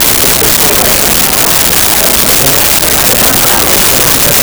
Bar Crowd Loop 03
Bar Crowd Loop 03.wav